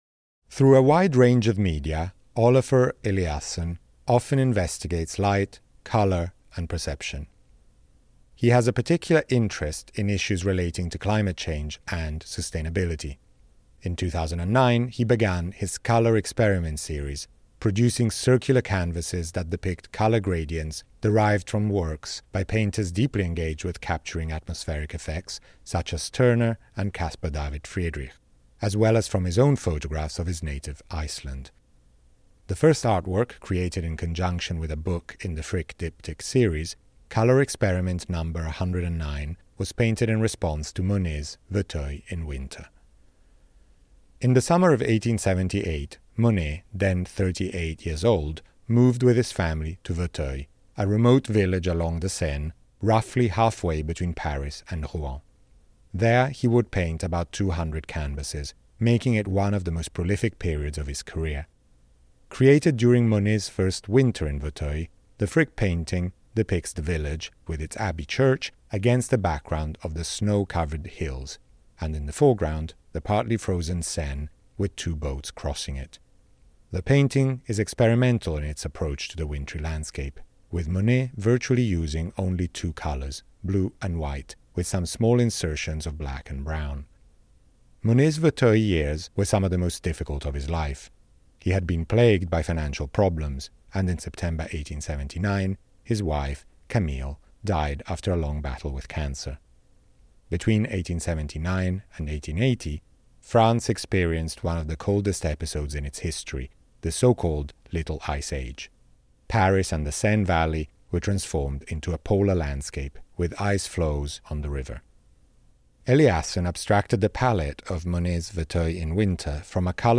528 — Spoken Label